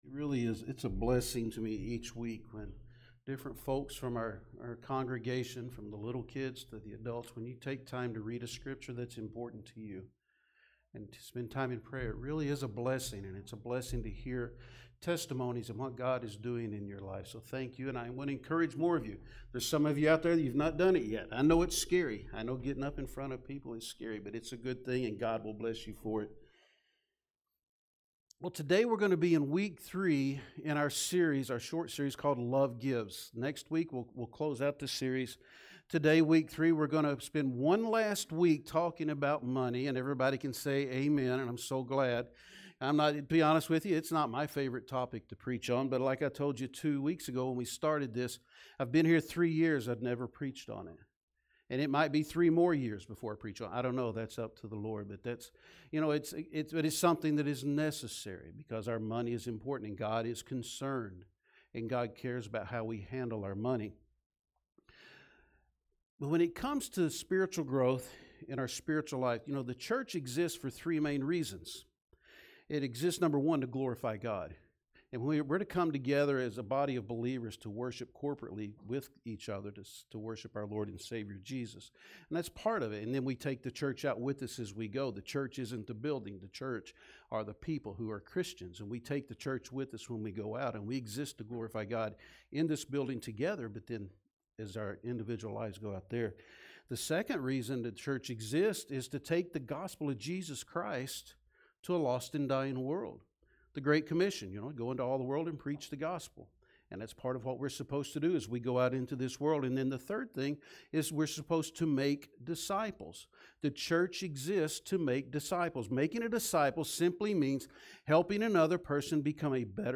Sermons | Summitville First Baptist Church